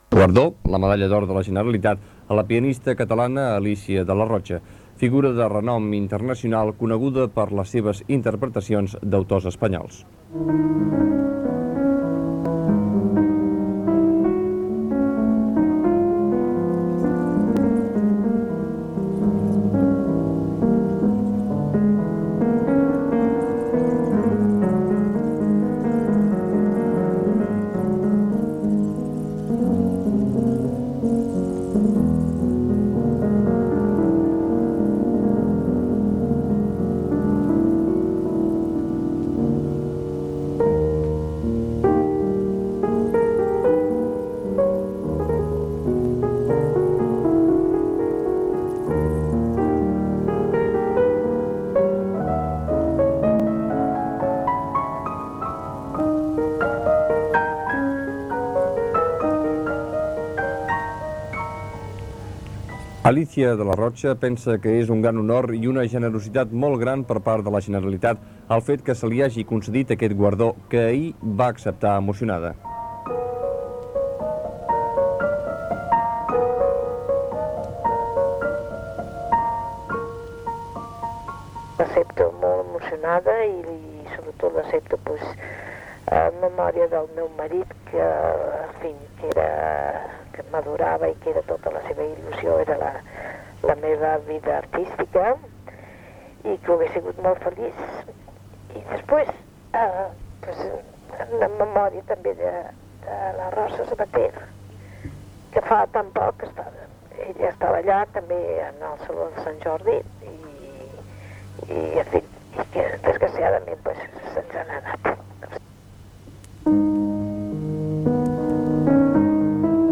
Informació del lliurament a la pianista Alícia de la Rocha de la medalla d'or de la Generalitat de Catalunya (amb declaracions de la pianista), comiat del programa
Informatiu
FM